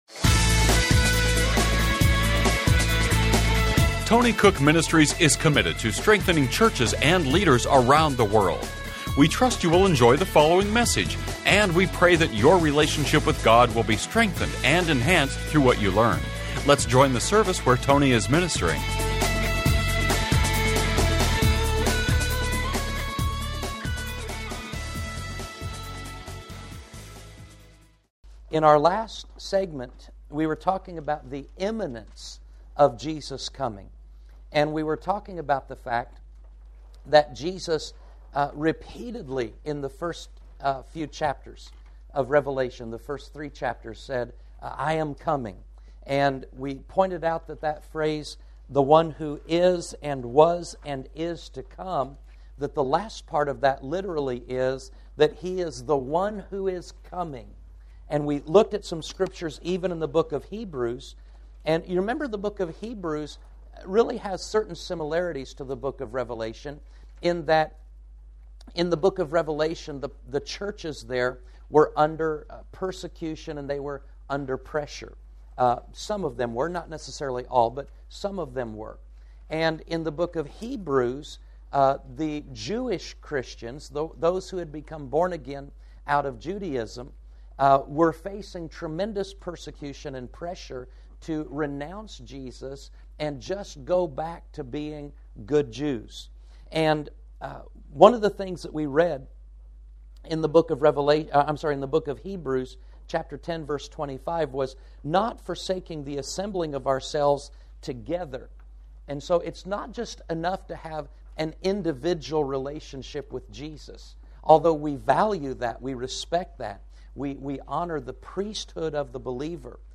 Genre: Christian Teaching.